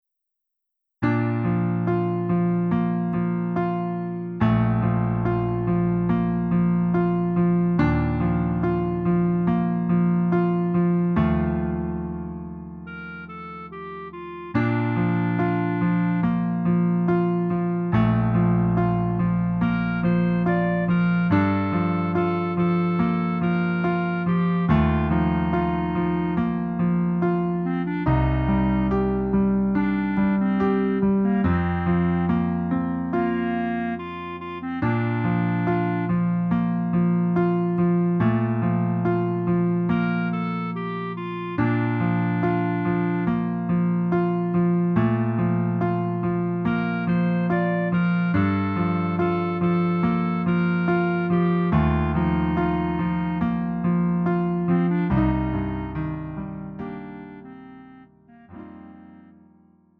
음정 원키 3:19
장르 가요 구분 Lite MR